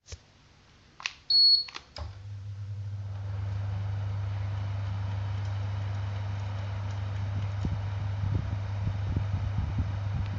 开启空调
描述：打开空调
标签： 空气 噪音 冷却器